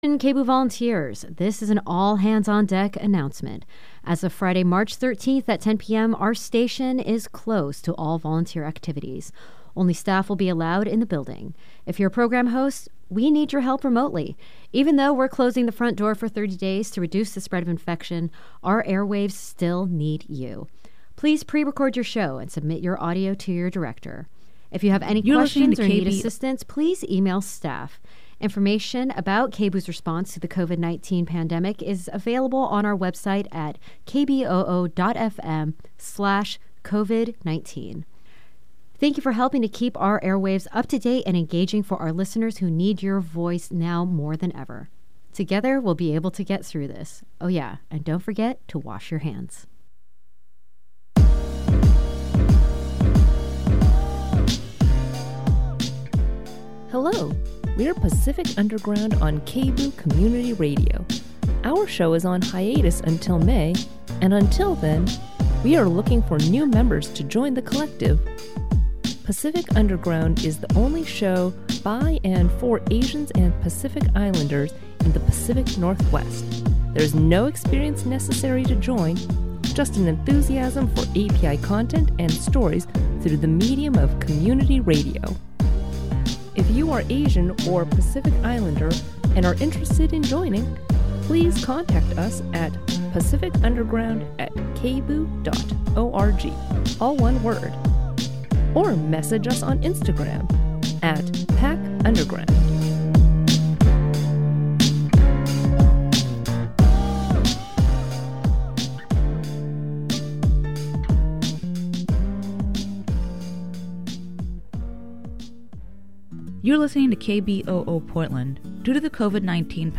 So, we will read the first two chapeters of George Simenon's Inspector Maigret mystery "The Grand Banks Cafe".